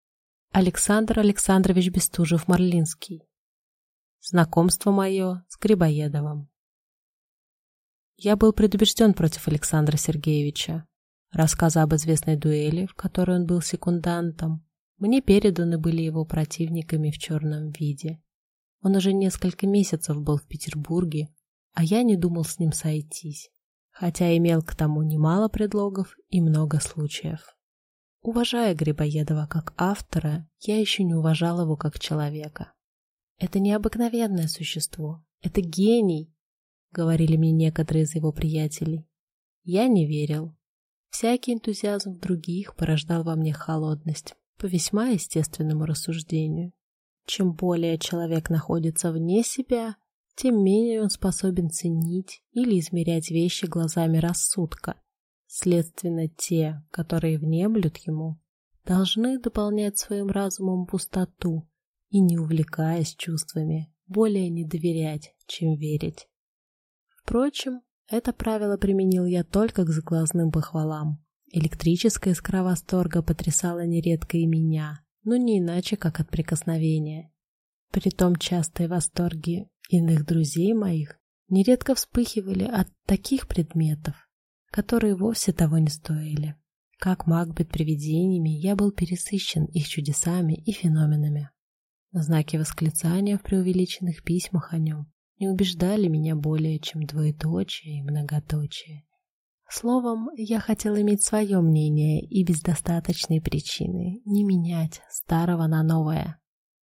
Аудиокнига Знакомство мое с А. С. Грибоедовым | Библиотека аудиокниг